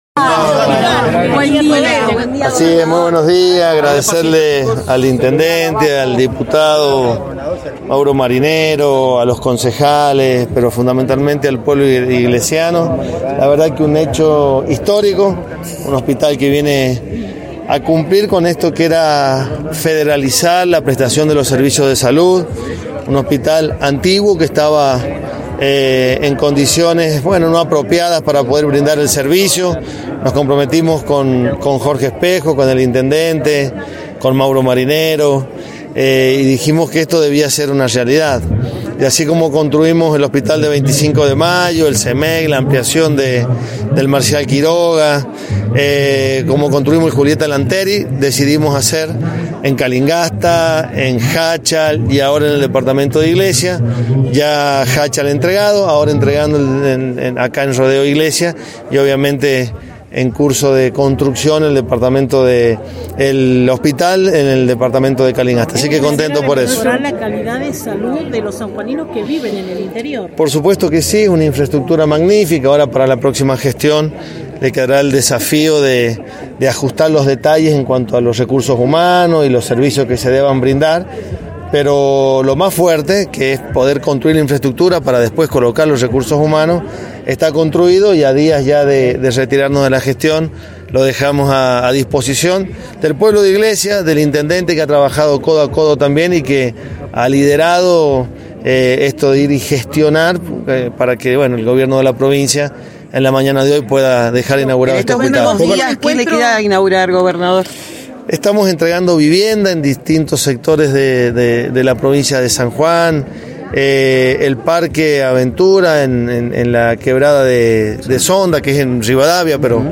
AUDIO| En la mañana de este Jueves el gobernador Uñac dejo inaugurado el nuevo Hospital Dr. Tomás Perón en el departamento de Iglesia.
PALABRA DEL GOBERNADOR DE SAN JUAN SERGIO UÑAC